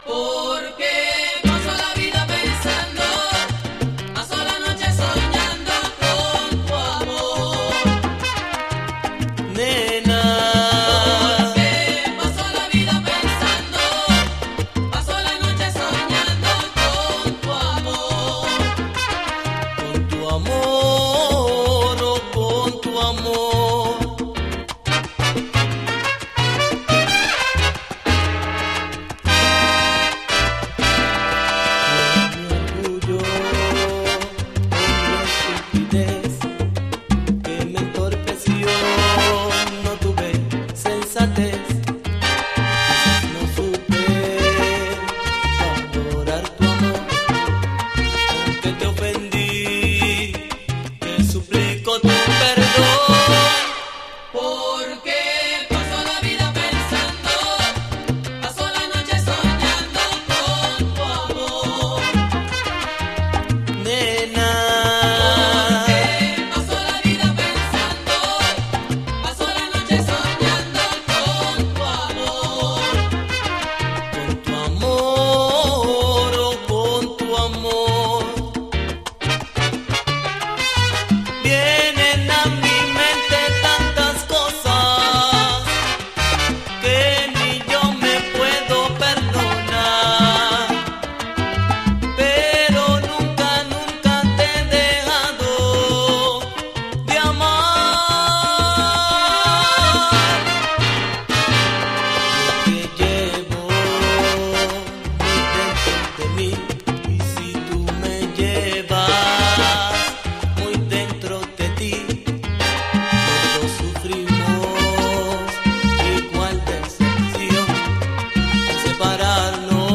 エレガントで軽やか！みずみずしく心洗い流すようなキューバン・ソン/トローヴァ！
みずみずしいガット・ギターの爪弾きと開放感たっぷりのヴォーカルが絡む、心を洗い流すようなサウンド。